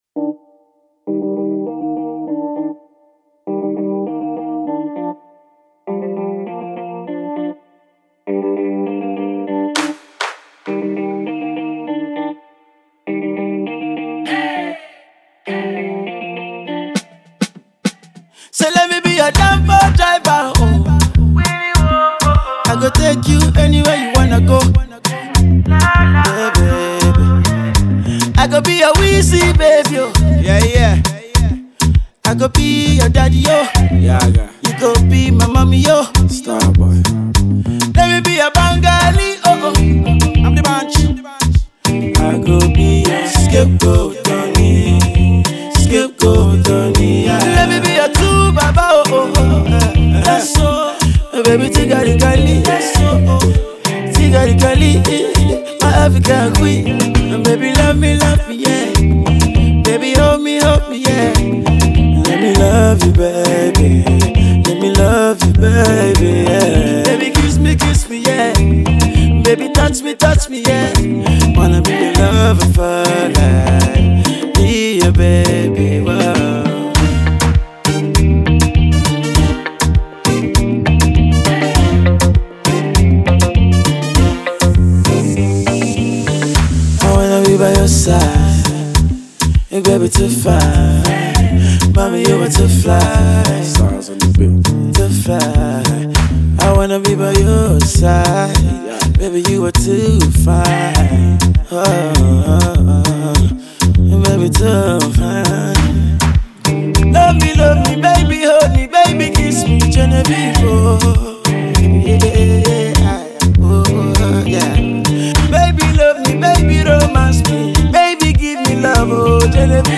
Naija Music